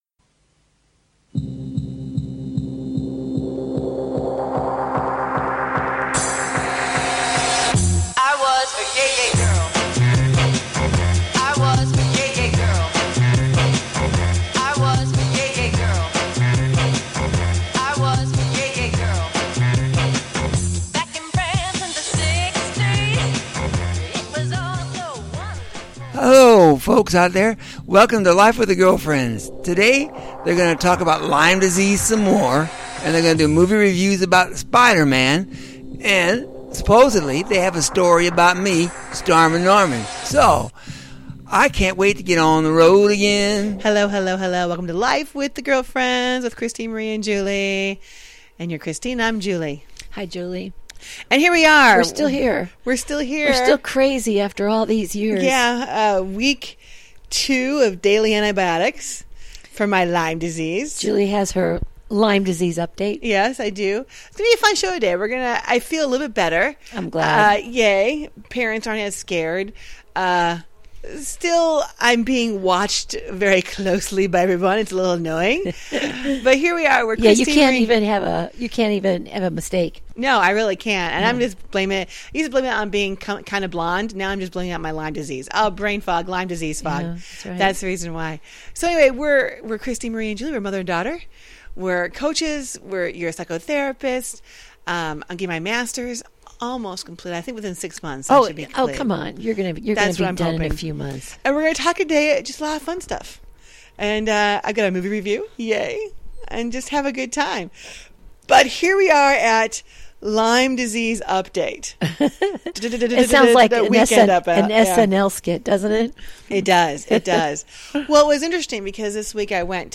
They welcome a wide range of guest to their den for some juicy conversation.
And join the girlfriends up close and personal for some daily chat that’s humorous, wholesome, and heartfelt.